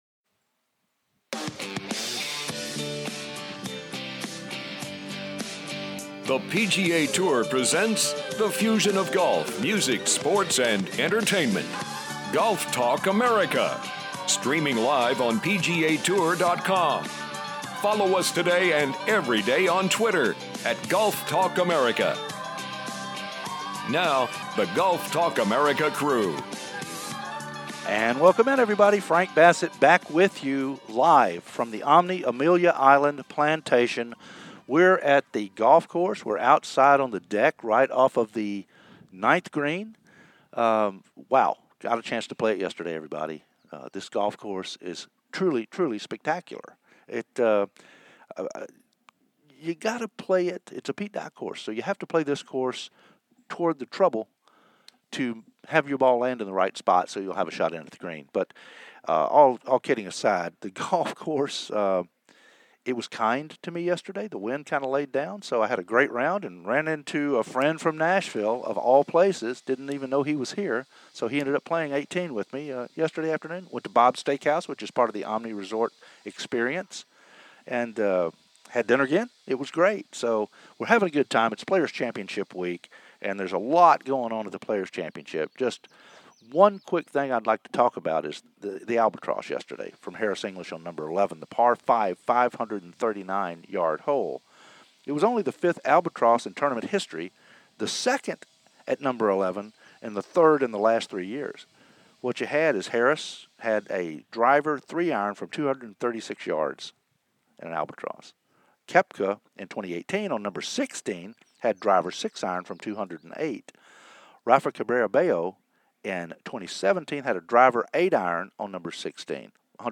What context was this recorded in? "LIVE" from The Omni Amelia Island Resort / Oak Marsh Dye Course